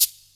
TSW CABASA.wav